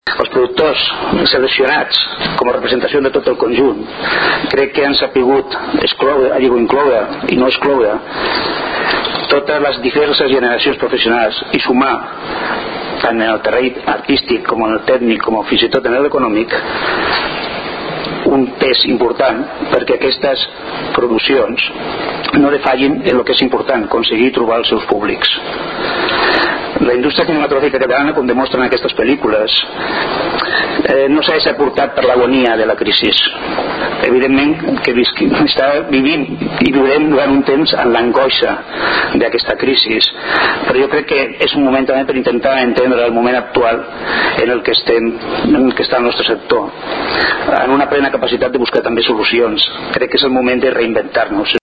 Intervencions durant la roda de premsa
Tall de veu